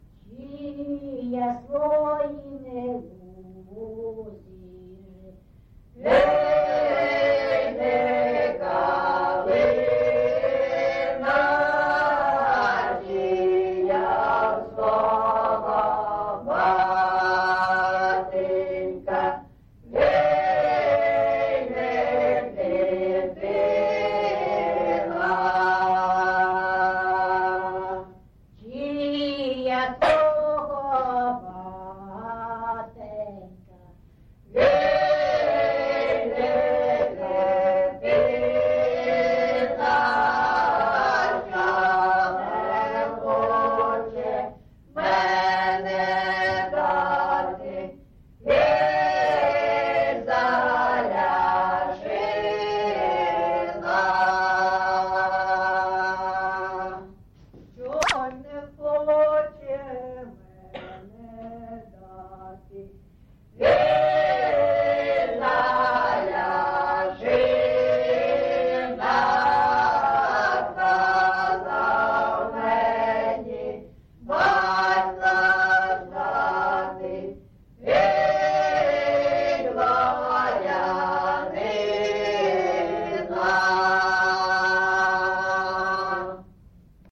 GenrePersonal and Family Life
Recording locationAndriivka, Velykа Novosilka district, Donetsk obl., Ukraine, Sloboda Ukraine